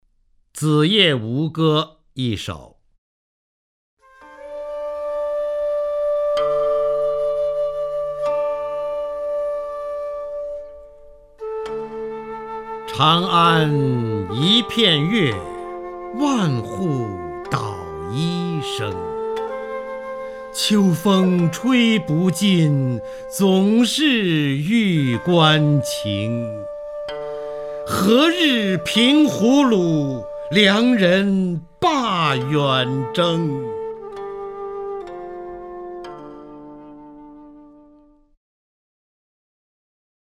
方明朗诵：《子夜吴歌·秋歌》(（唐）李白) （唐）李白 名家朗诵欣赏方明 语文PLUS